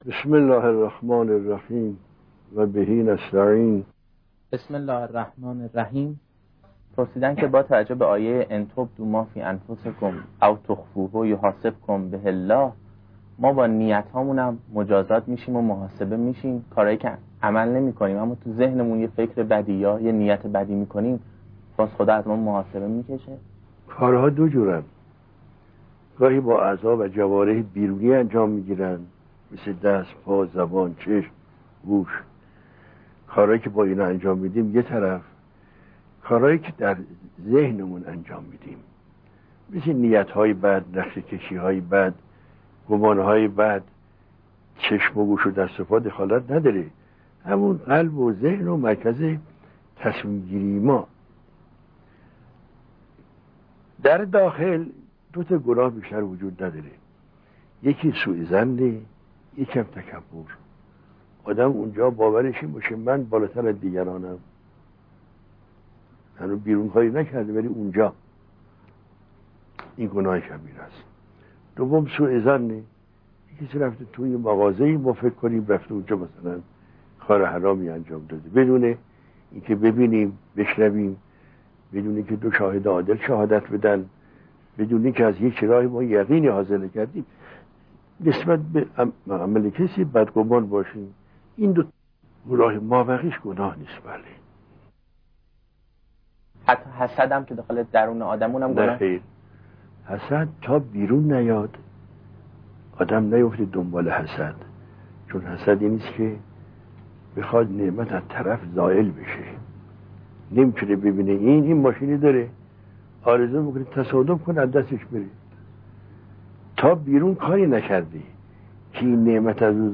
در یکی از دروس اخلاق به پرسش و پاسخی پیرامون «محاسبه نیت‌ها و افکار» پرداختند که متن آن بدین شرح است: